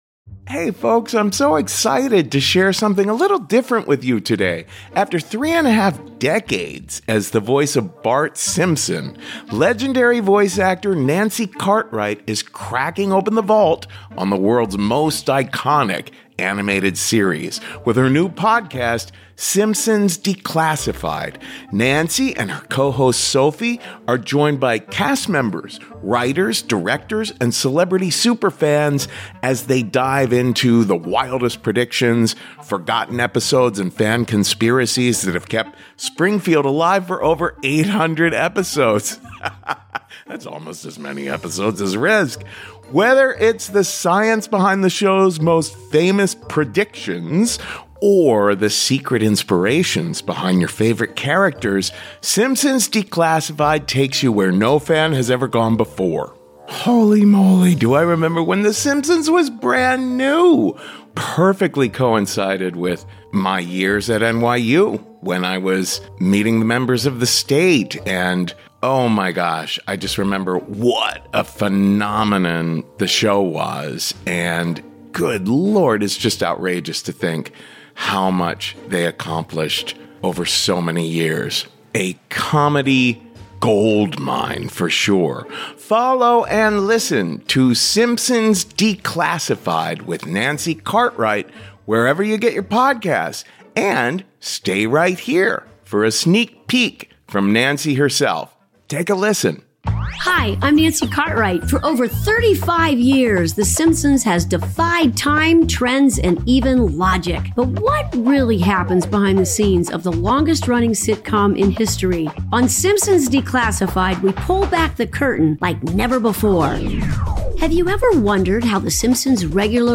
I’m Nancy Cartwright, the voice of Bart Simpson for 35+ years, and I’m finally taking you inside Springfield. Simpsons Declassified is your all-access pass to the world’s most iconic, animated series.